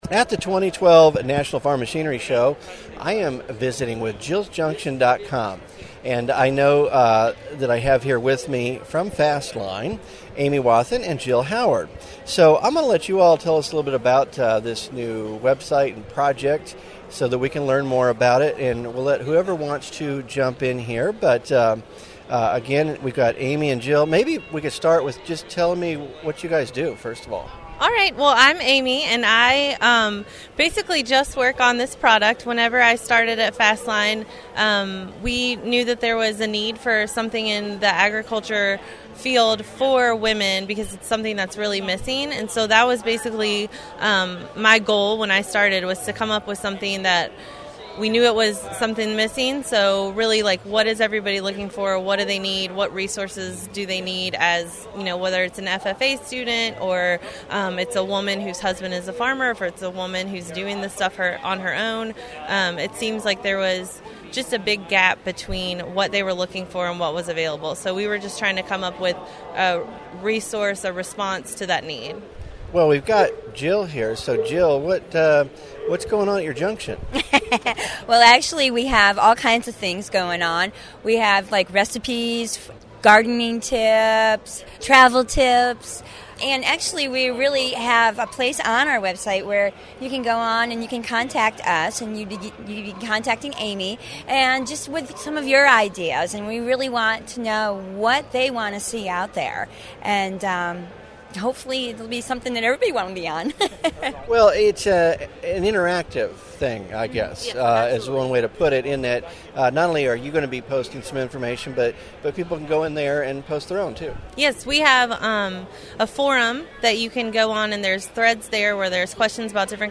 They are here on location at the 2012 National Farm Machinery Show to introduce this new online destination to attendees.